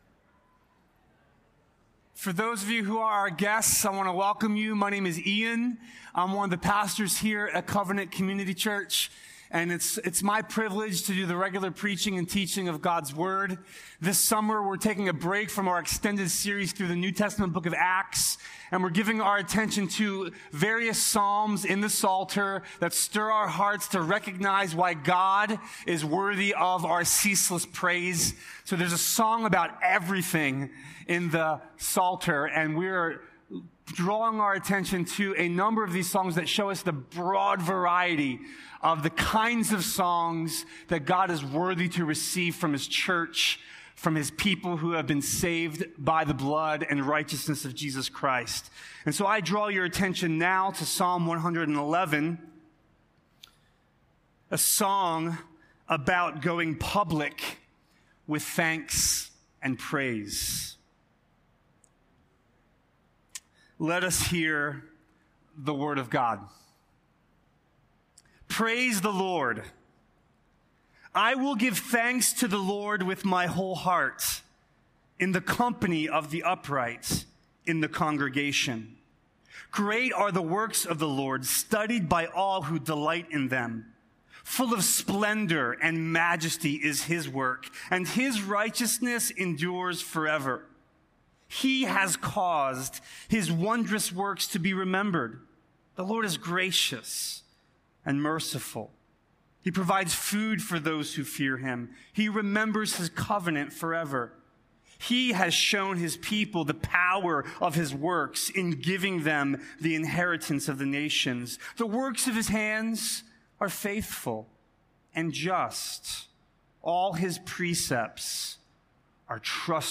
A sermon from the series "The Psalms." Psalm 130 is written out of the depths, where we can find ourselves believing that we're alone.